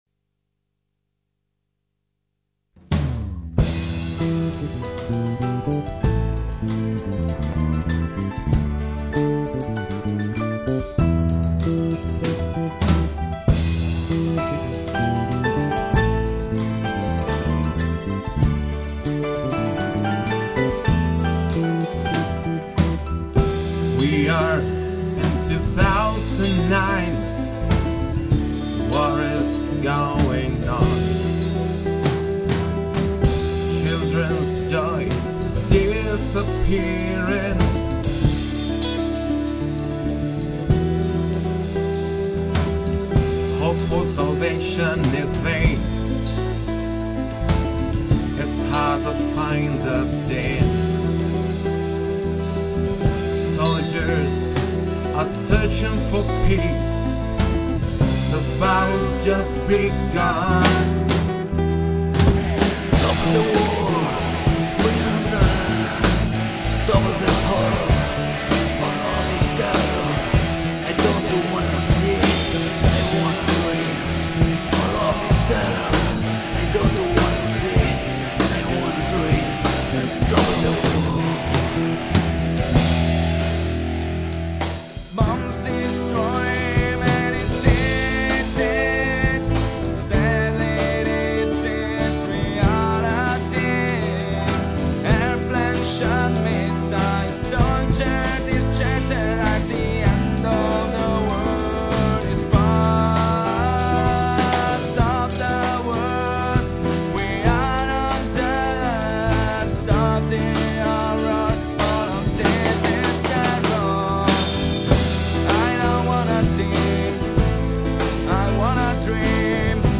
Luogo esecuzioneFaenza(RA)
GenereRock / Metal